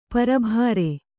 ♪ parabhāre